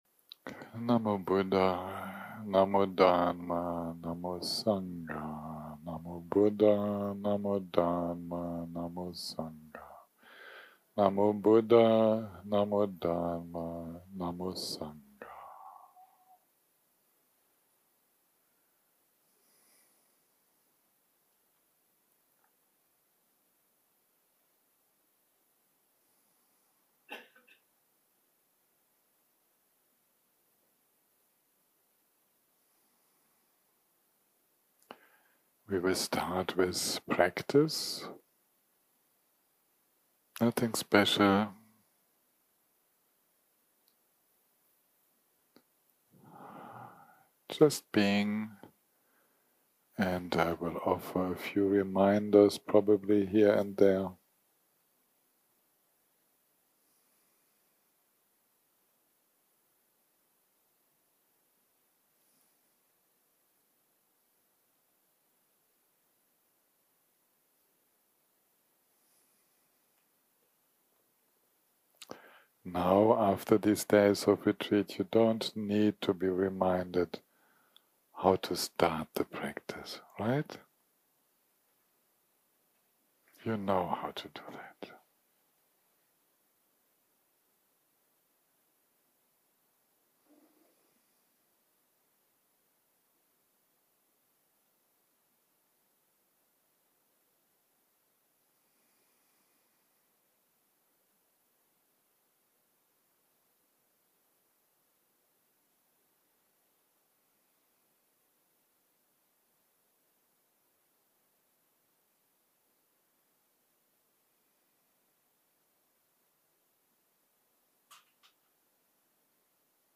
יום 8 - הקלטה 39 - צהרים - מדיטציה מונחית - Tong Len 7
Guided meditation